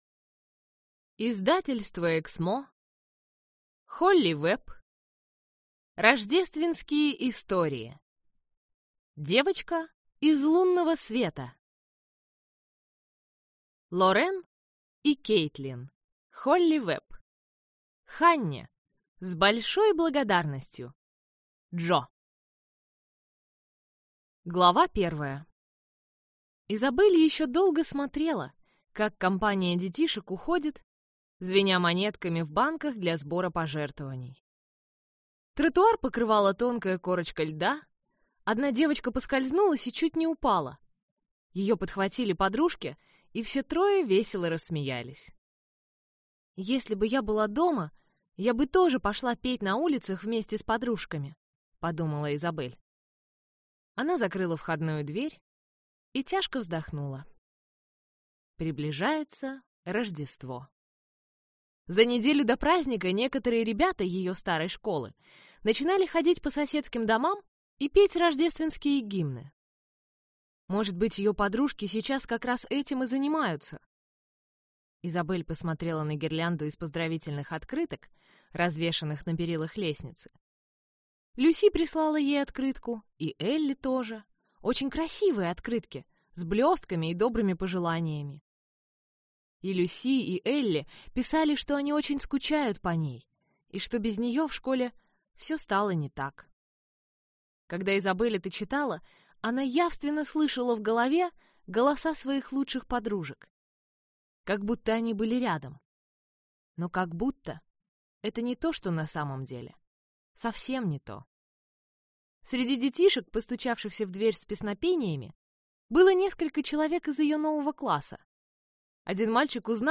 Аудиокнига Рождественские истории. Девочка из лунного света | Библиотека аудиокниг
Прослушать и бесплатно скачать фрагмент аудиокниги